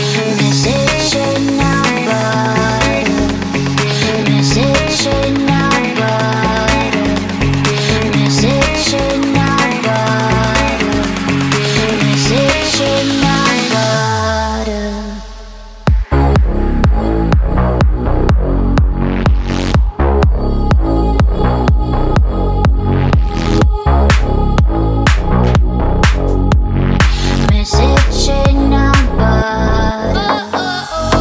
• Качество: 128, Stereo
танцевальная